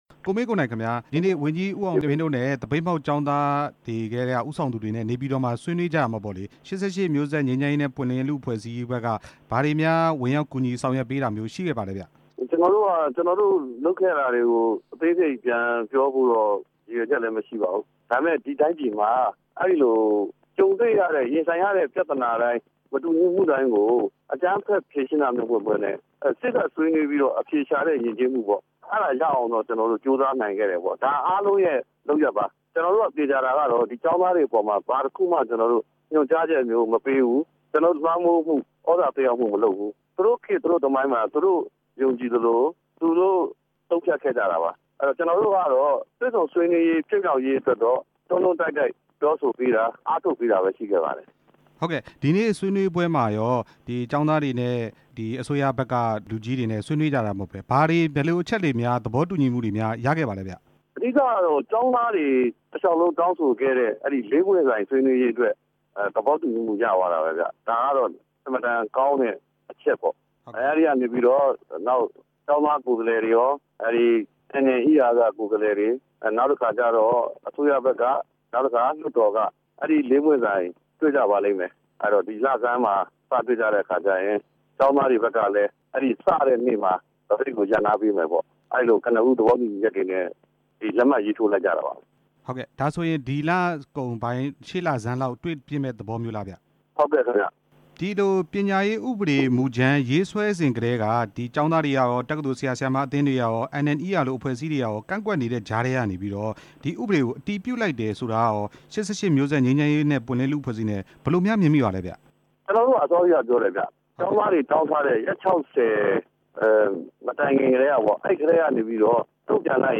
တွေ့ဆုံဆွေးနွေးပွဲ တက်ရောက်ခဲ့တဲ့ ကိုမင်းကိုနိုင်ကို မေးမြန်းချက်